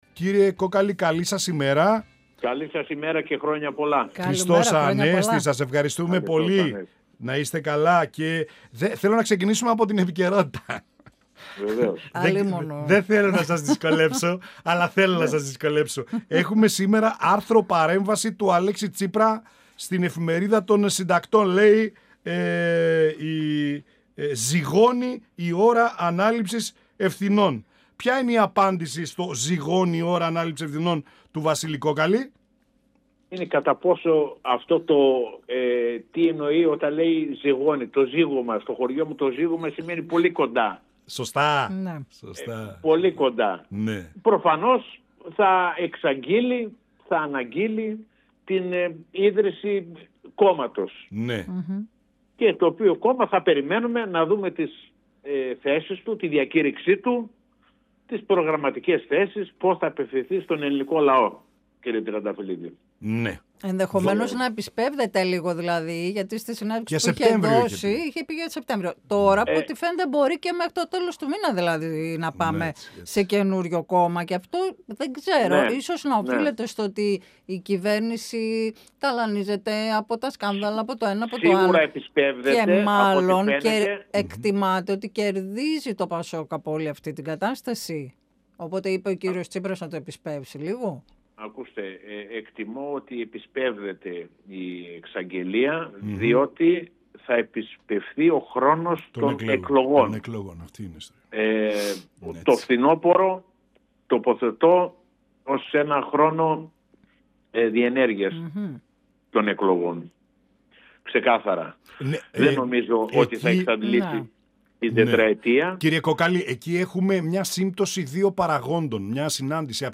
εκπομπή «Πανόραμα Επικαιρότητας» του 102FM της ΕΡΤ3.